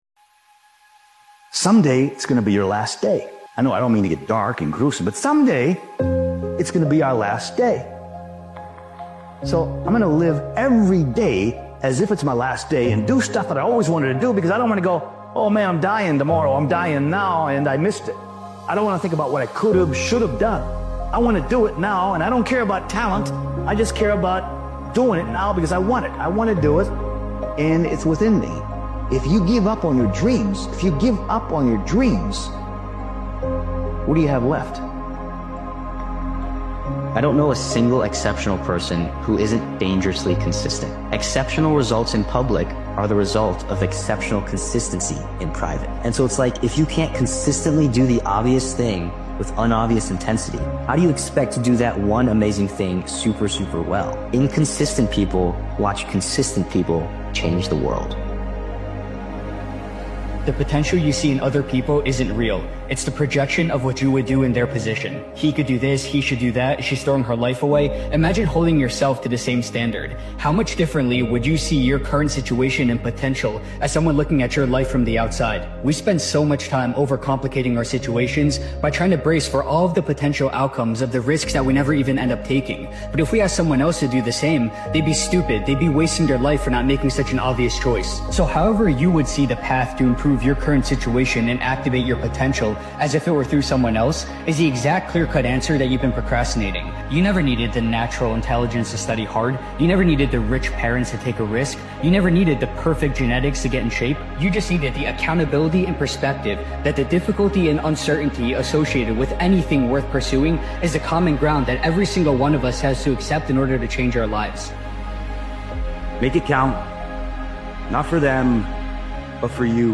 Powerful Motivational Speech Video is a raw and unapologetic motivational episode created and edited by Daily Motivations. This impactful motivational speeches compilation is a reminder that the vision you once dreamed of isn’t just a thought—it’s a commitment. Discipline, consistency, and mindset are the tools you need to bridge the gap between where you are and where you promised yourself you’d be.